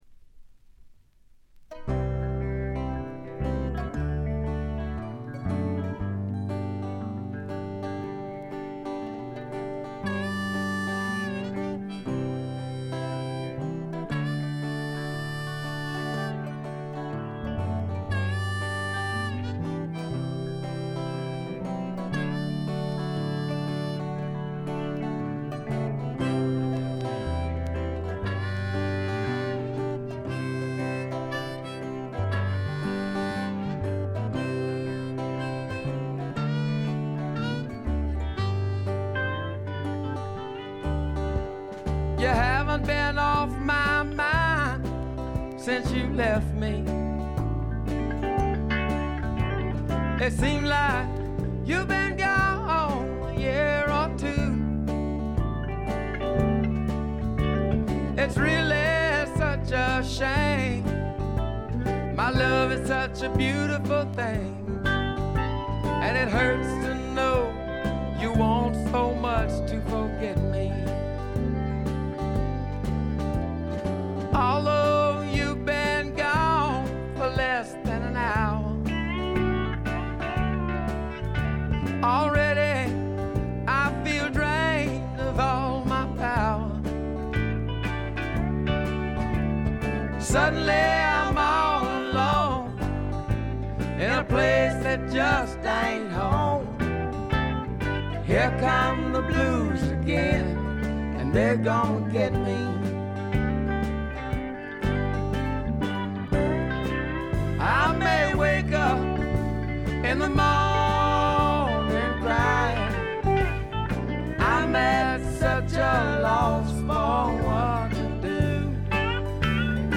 B3終盤でプツ音2連。
びしっと決まった硬派なスワンプ・ロックを聴かせます。
試聴曲は現品からの取り込み音源です。
Recorded at Paramount Recording Studio.